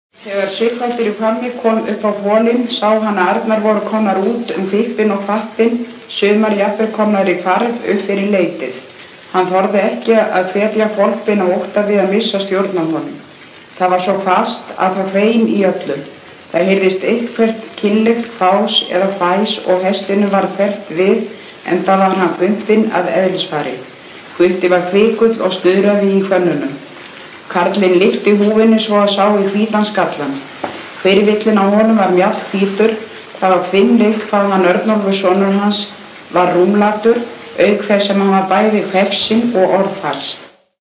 Mállýskudæmi
Vestur-Skaftfellingar
Dæmi 1: Kona les texta um Sighvat í Hvammi á 9. áratug 20. aldar (Málhafi nr. 6 í Mállýskudæmum bls. 35):